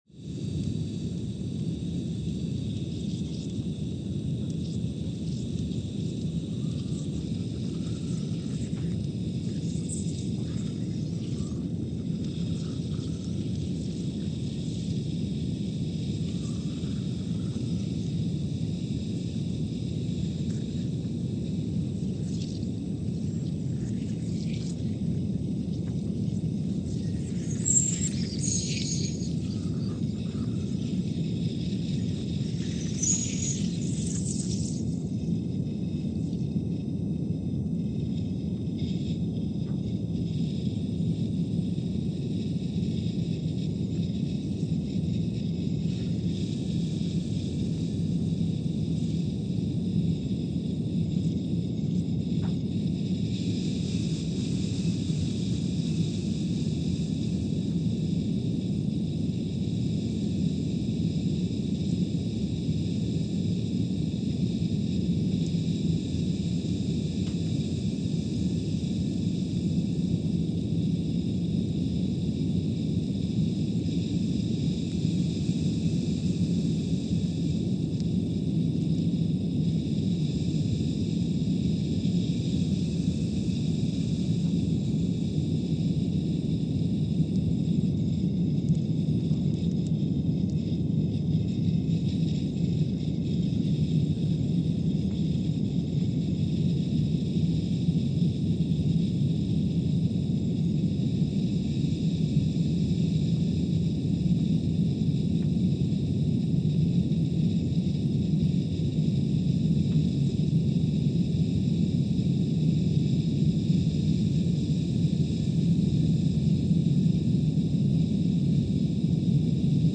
Scott Base, Antarctica (seismic) archived on January 5, 2021
Sensor : CMG3-T
Speedup : ×500 (transposed up about 9 octaves)
Loop duration (audio) : 05:45 (stereo)
SoX post-processing : highpass -2 90 highpass -2 90